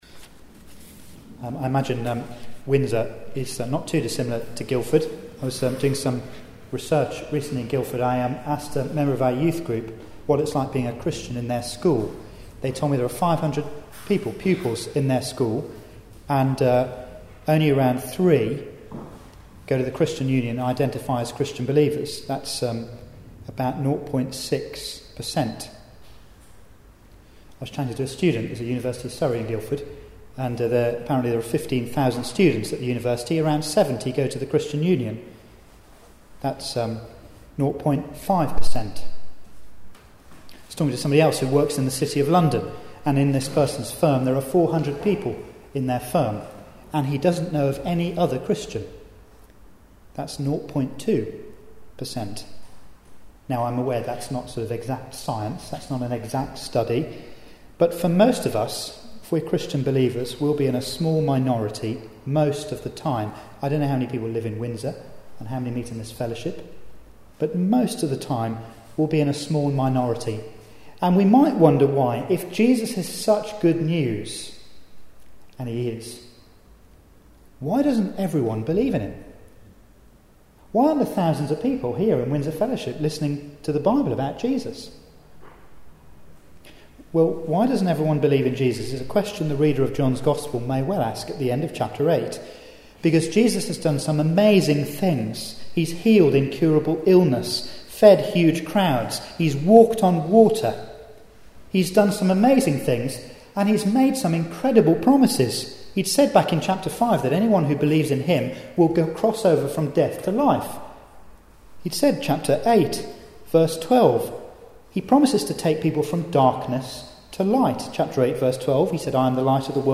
John 9:39 Service Type: Weekly Service at 4pm Bible Text